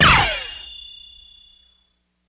Generic Lasers
Laser With Blade Ricochets